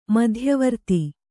♪ madhyavarti